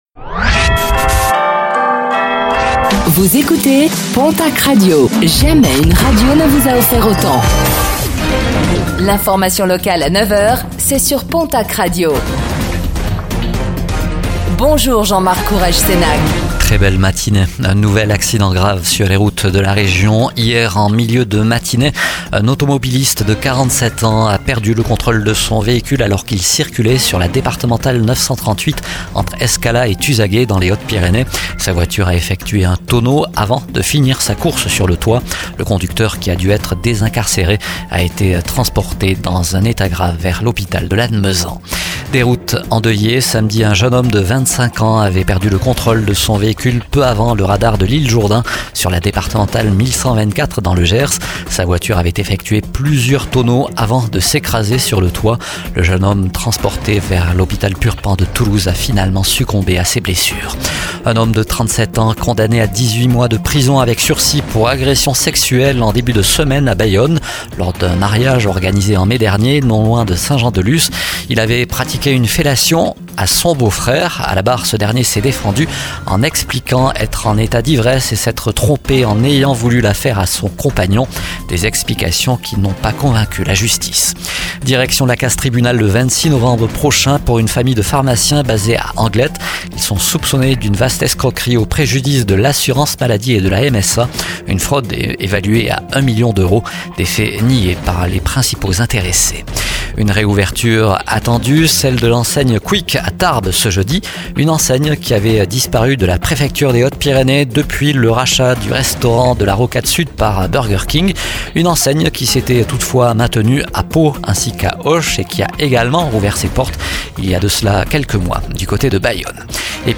09:05 Écouter le podcast Télécharger le podcast Réécoutez le flash d'information locale de ce jeudi 26 septembre 2024